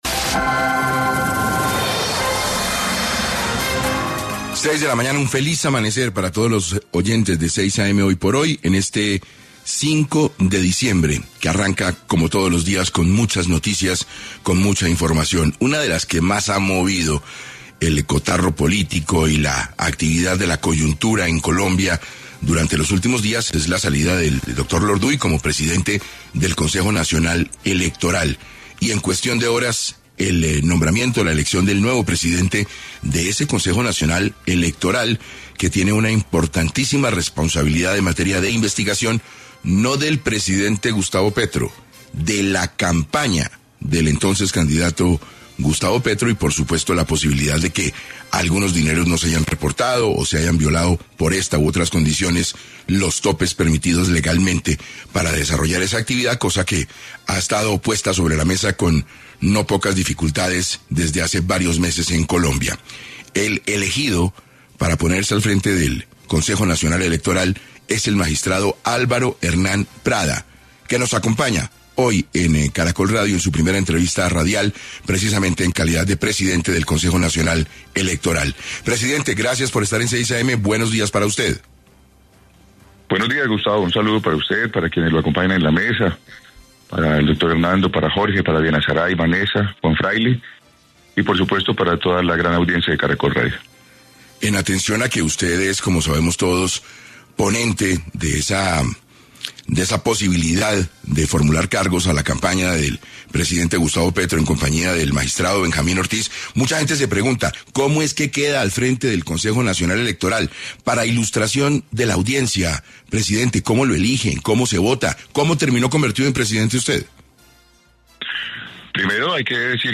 Prada concedió su primera entrevista radial en exclusiva para el noticiero ‘6 AM’ de Caracol Radio.